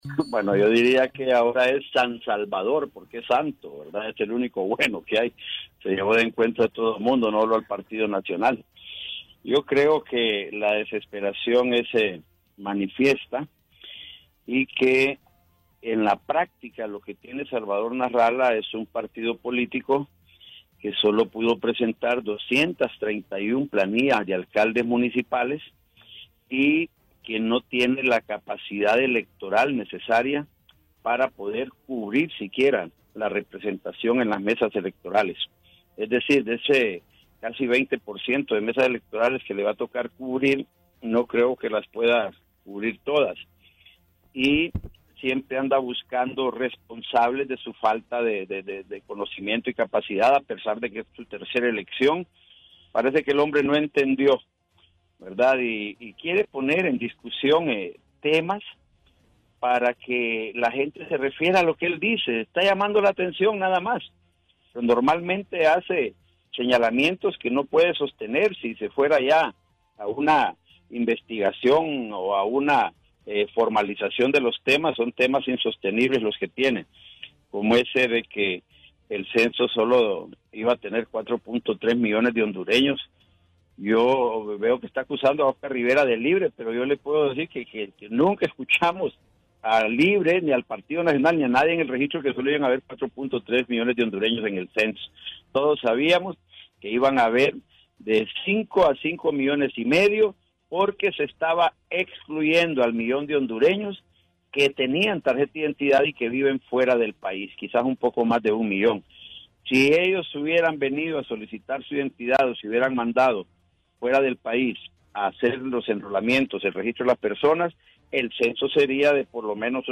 Aquí las declaraciones del dirigente de Partido Nacional, Fernando Anduray y del candidato a la presidencial, Salvador Nasralla